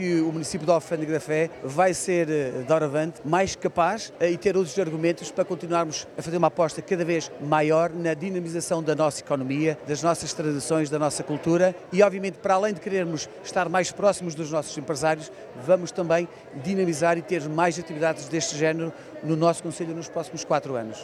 O presidente da Câmara Municipal, Eduardo Tavares, afirmou que a Festa da Montanha será uma aposta a manter no próximo mandato, agora que os problemas financeiros da autarquia estão ultrapassados: